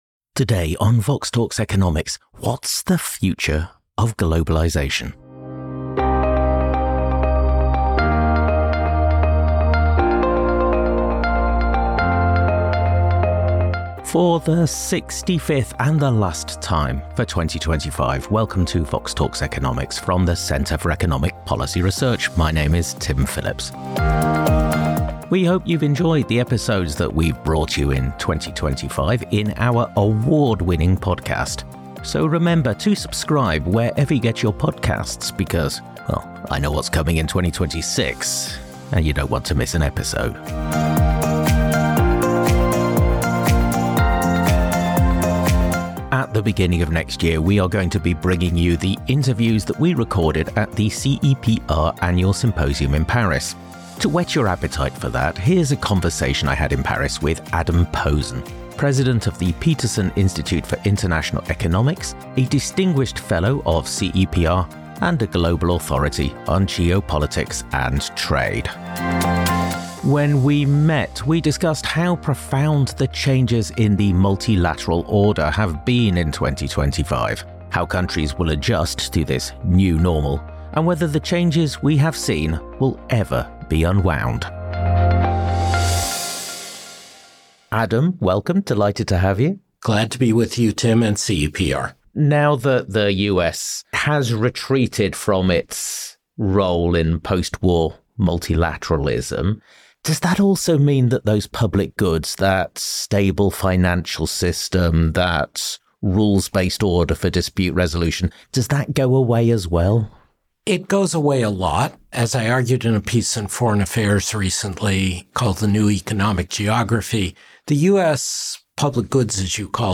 Headliner Embed Embed code See more options Share Facebook X Subscribe At the CEPR annual Symposium in Paris we sat down with Adam Posen, president of the Peterson Institute for International Economics, a distinguished fellow of CEPR, and a global authority on geopolitics and trade to discuss the profound changes in the multilateral order in 2025, how countries will adjust to this new normal – and whether the changes we have seen will ever be unwound.